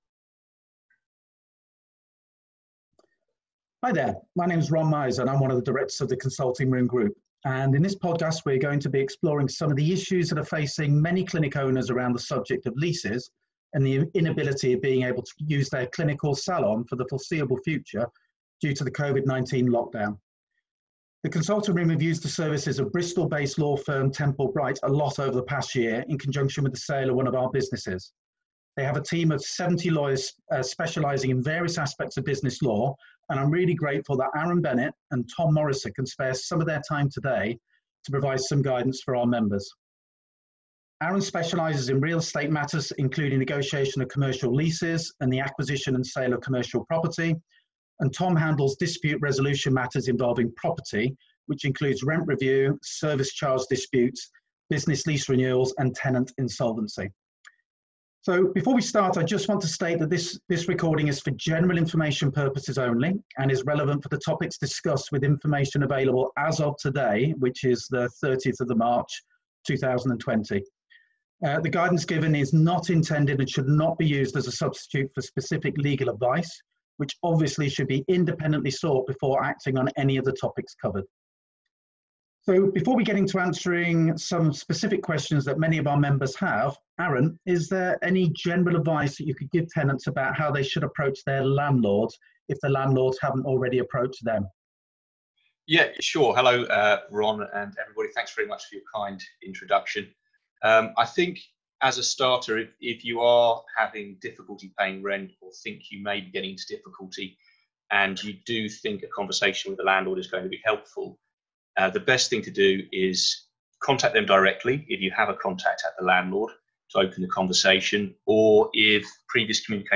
Temple Bright solicitors discuss the approach that both tenants and landlords need to take today.